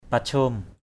/pa-ʧo:m/ (đg.) hy vọng = espérer. hope. halei dahlak oh pachom tra ka drei (DWM) hl] dh*K oH p_S’ t% k% d] tôi chẳng còn hy vọng gì nữa vào...